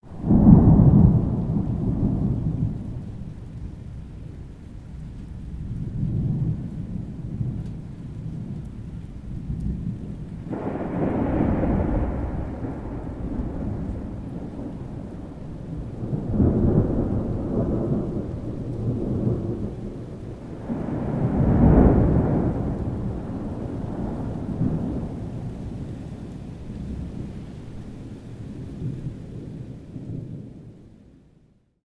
thunder_1.ogg